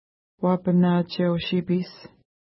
ID: 7 Longitude: -59.5666 Latitude: 52.7587 Pronunciation: wa:pəna:tʃew-ʃi:pi:s Translation: Sneaking Creature River (small) Feature: river Explanation: Named in reference to lake Uapanatsheu-nipi (no 5) from which it flows.